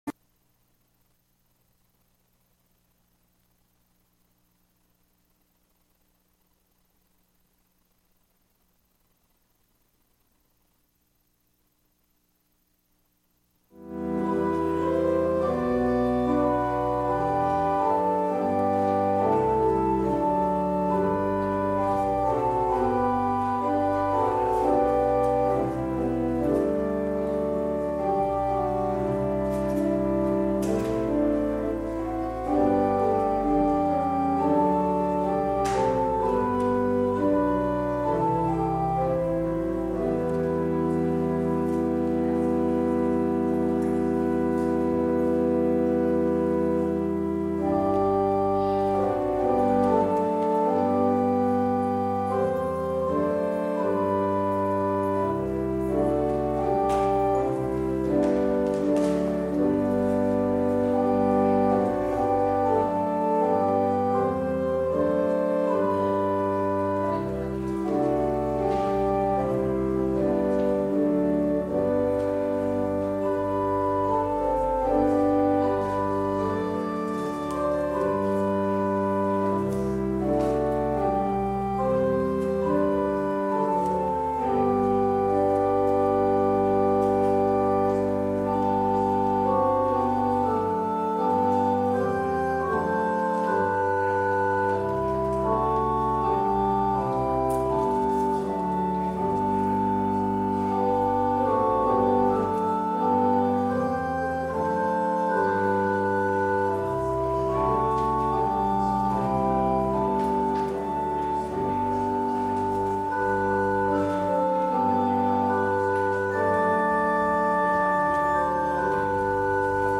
Public Reading of Holy Scripture